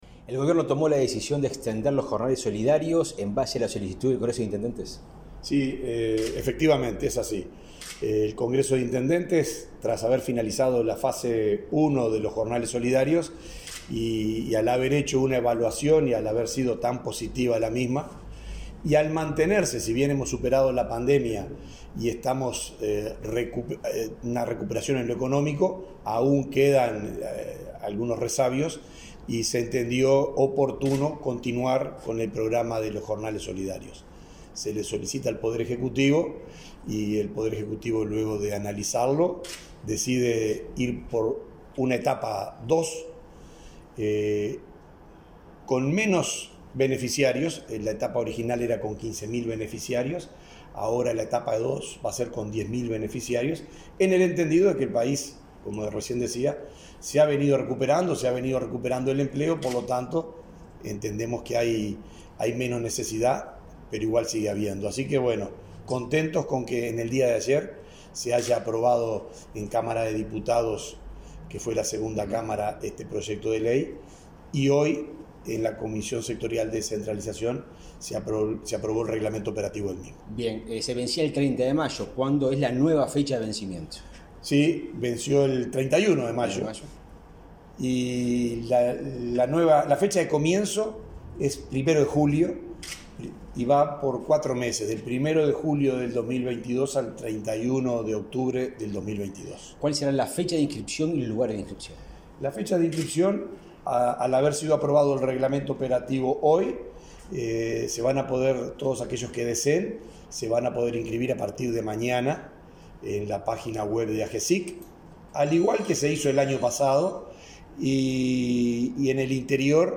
Entrevista al subdirector de la Oficina de Planeamiento y Presupuesto, Benjamín Irazabal